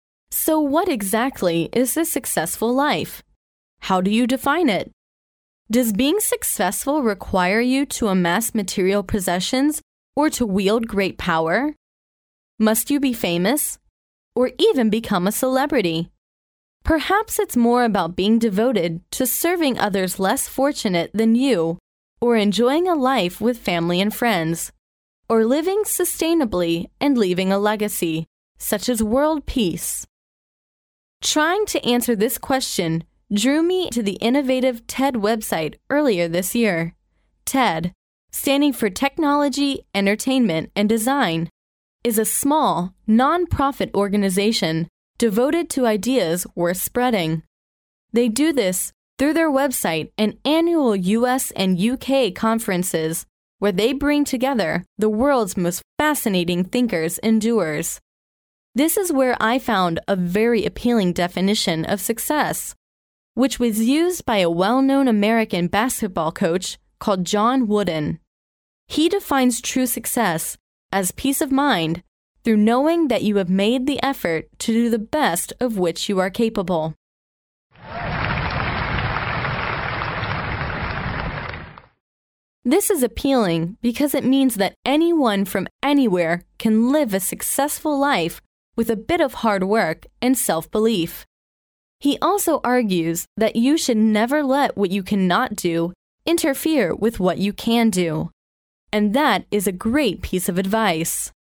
在线英语听力室名人励志英语演讲 第83期:为成功做好准备(2)的听力文件下载,《名人励志英语演讲》收录了19篇英语演讲，演讲者来自政治、经济、文化等各个领域，分别为国家领袖、政治人物、商界精英、作家记者和娱乐名人，内容附带音频和中英双语字幕。